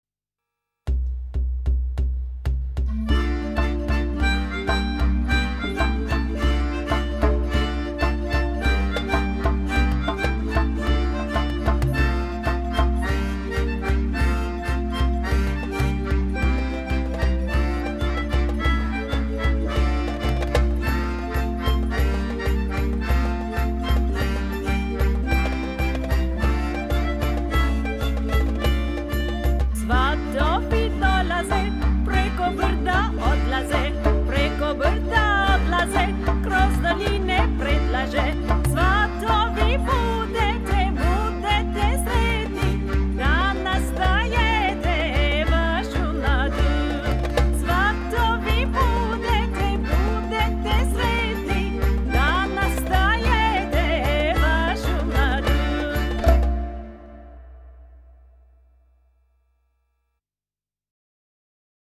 Melodion
Harmonica
and Qchord